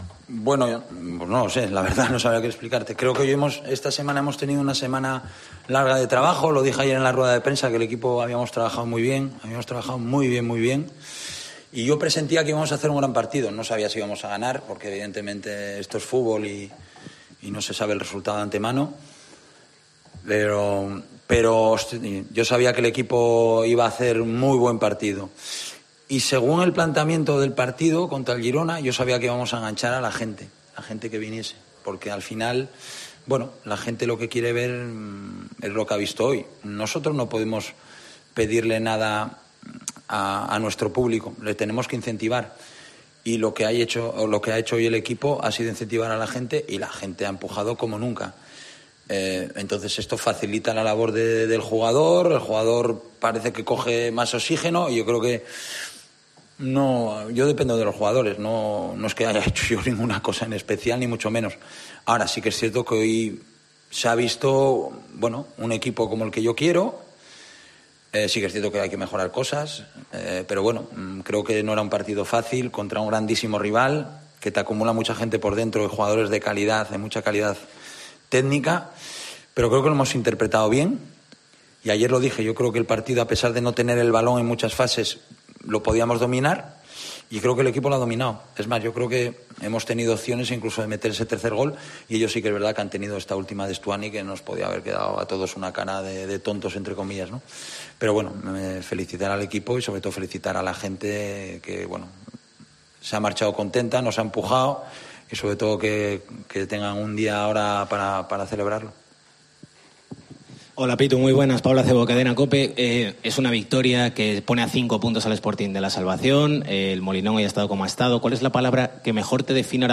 Rueda de prensa Abelardo (post Girona)